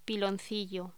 Locución: Piloncillo
voz